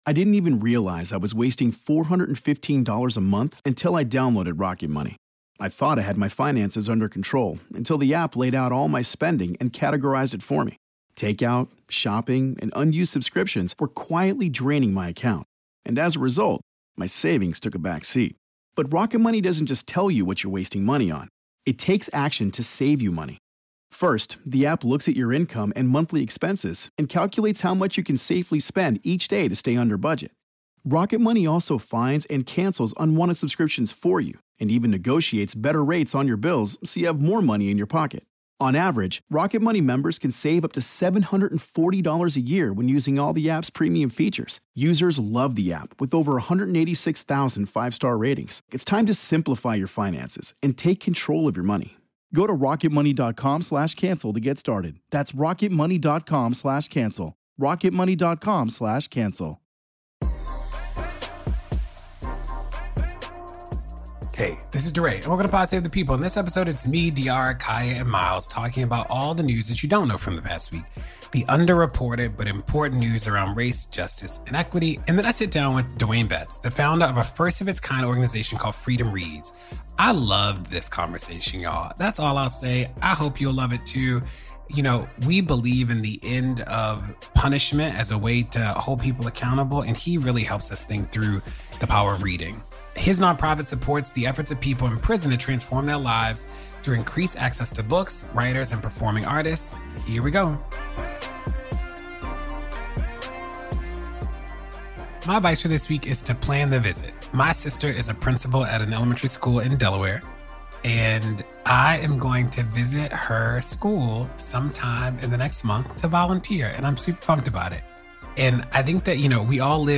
DeRay interviews activist and founder Dwayne Betts about his non-profit organization Freedom Reads.